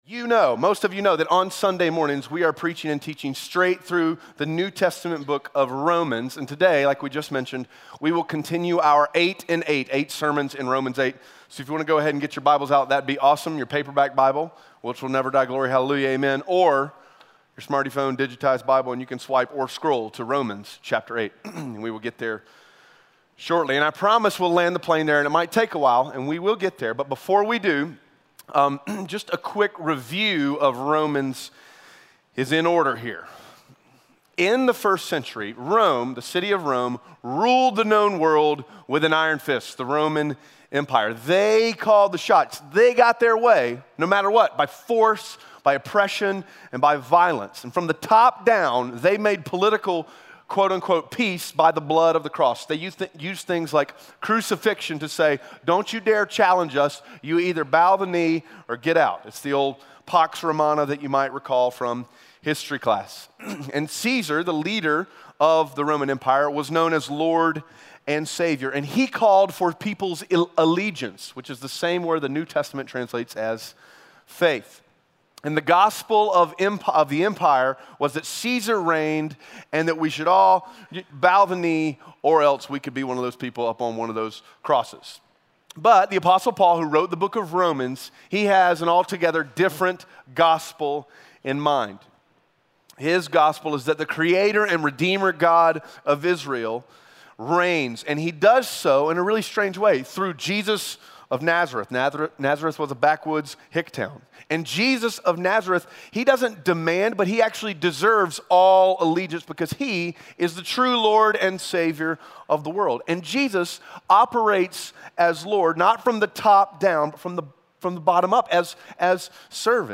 Romans 8:1-4 Audio Sermon Notes (PDF) Onscreen Notes Ask a Question *We are a church located in Greenville, South Carolina.